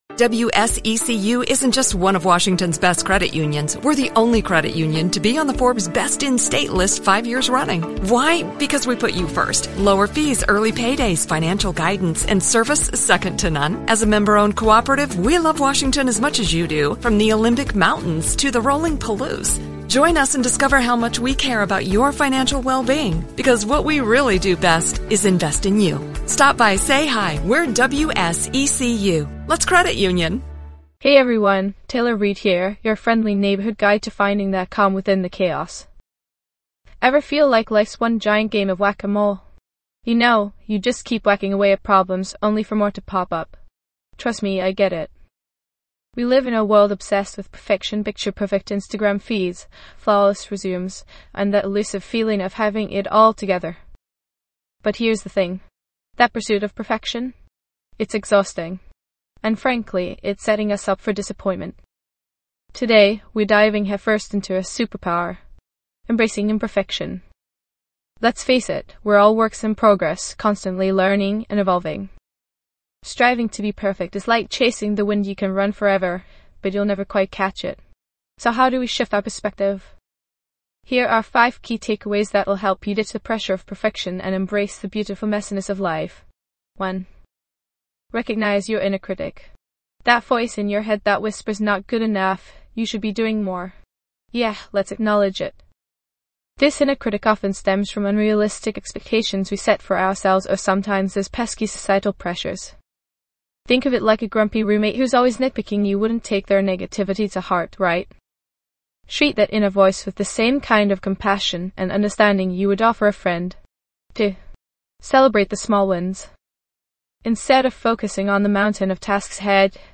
- Mindfulness & Meditation
This podcast is created with the help of advanced AI to deliver thoughtful affirmations and positive messages just for you.